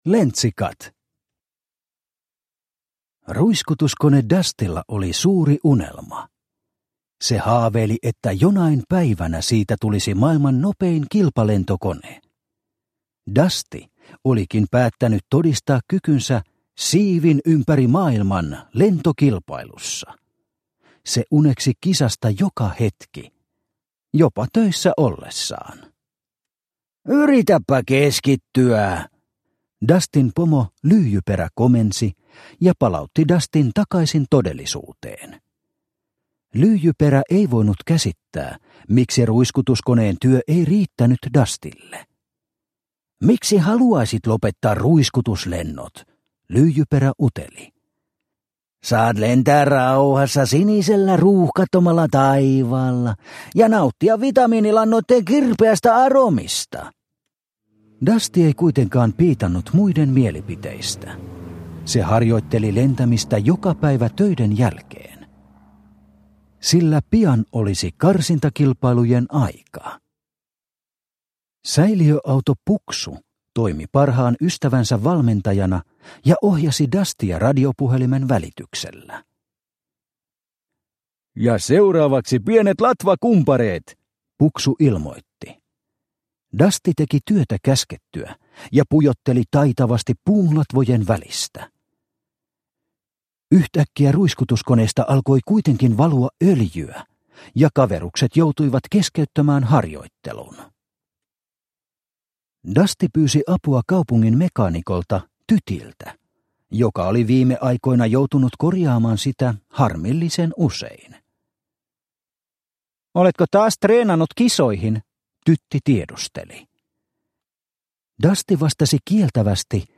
Lentsikat – Ljudbok – Laddas ner